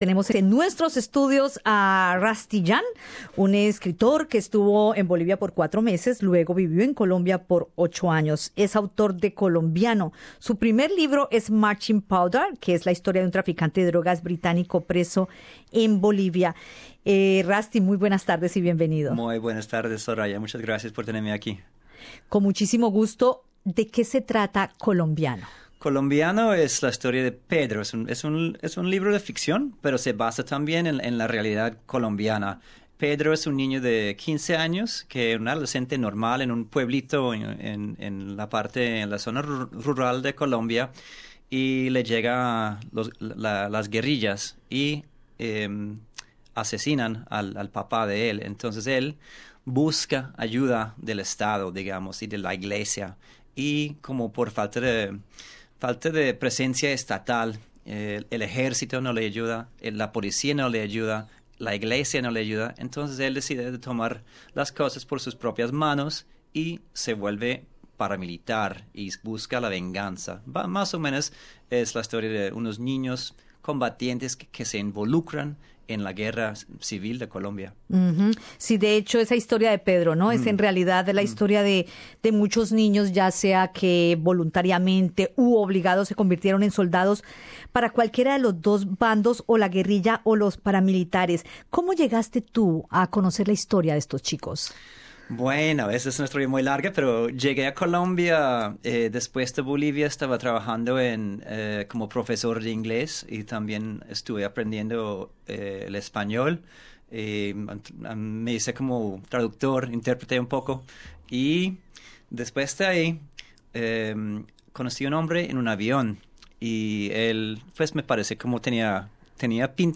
En entrevista con Radio SBS nos cuenta cómo un australiano termina involucrado en el conflicto colombiano y cuál es su percepción de lo que vivió.